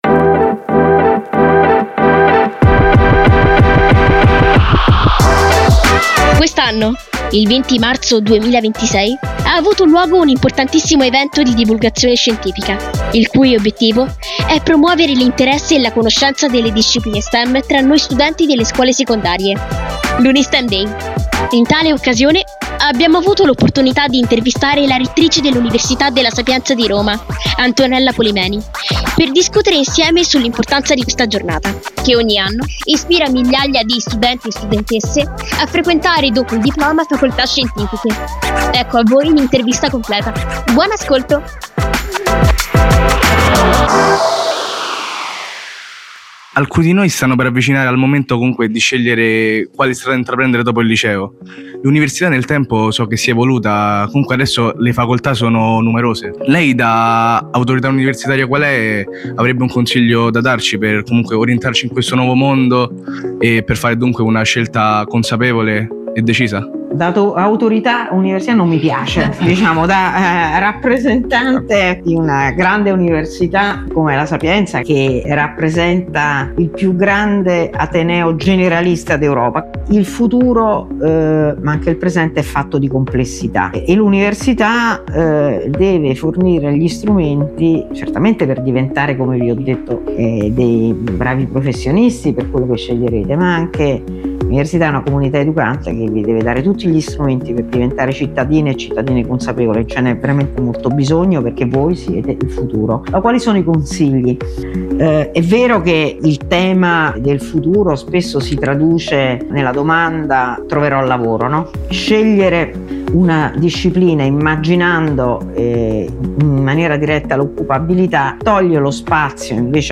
Unistemday 2026 - Intervista ad Antonella Polimeni